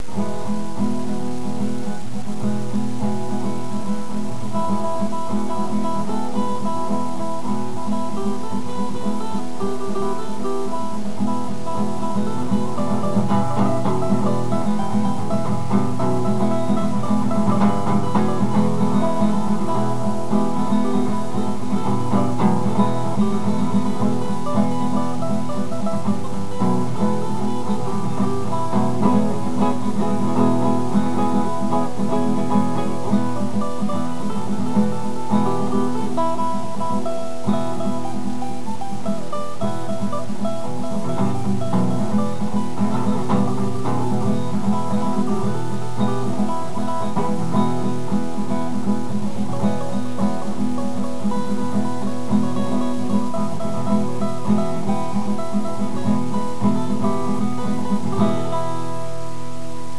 Original Guitar Works
These are some samples of me fiddling around on the guitar, recorded with the microphone in my laptop computer.